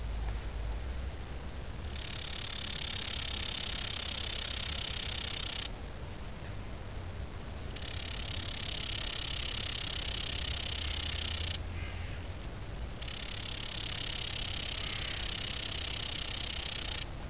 Abb. 05: Hier hinter dieser Brombeerwand verbirgt sich eine Grille. (FB)
Abb. 06: Das Frequenzspektrum dieser Grille: in einem breiten Band zwischen 1,5 und 5 kHz ist der Schall vertreten. Es gibt aber auch Strukturen in diesem Band mit zueinander parallel verlaufenden Streifen, sowie frequenzmodulierte Stellen etwa bei der Zeit-Position 9 Sekunden.